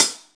paiste hi hat7 close.wav